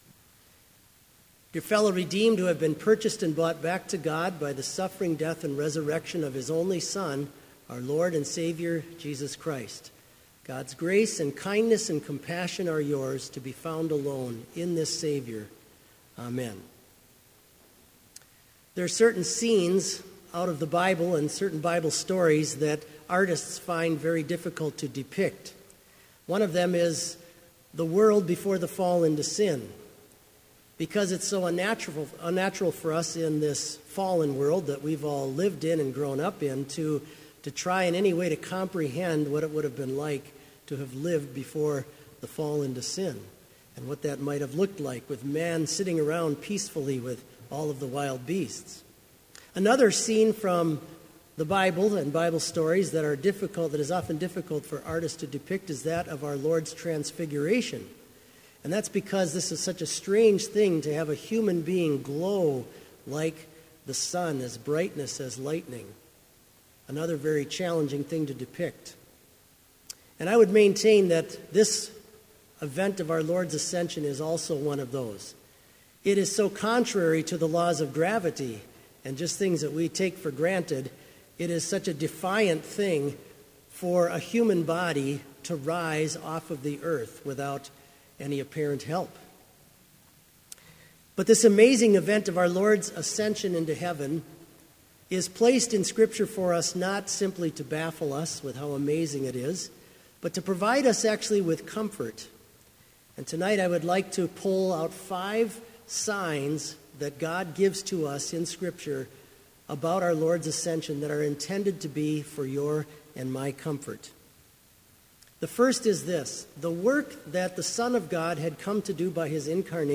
Sermon audio for Evening Vespers - May 4, 2016